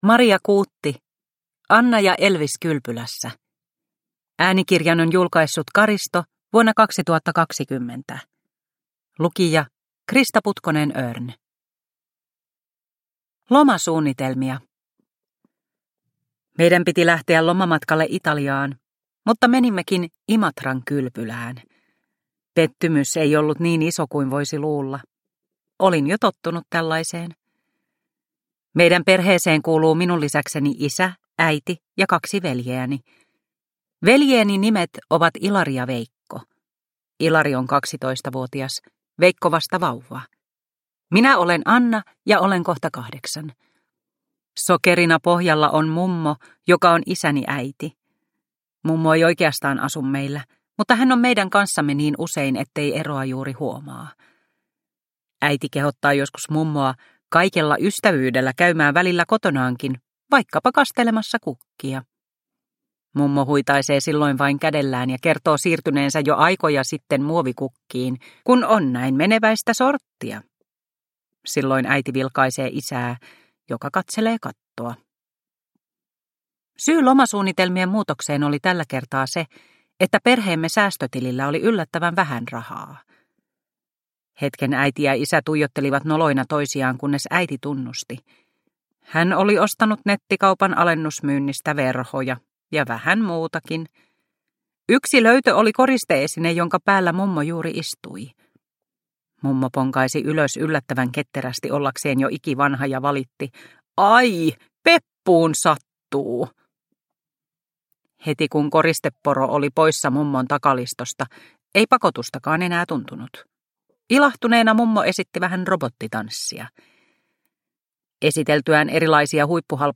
Anna ja Elvis kylpylässä – Ljudbok – Laddas ner